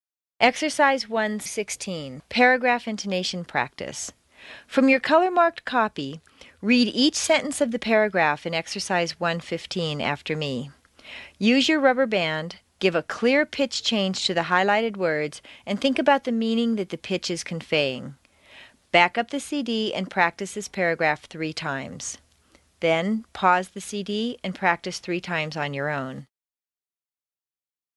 Exercise 1-16: Paragraph Intonation Practice CD 1 Track 25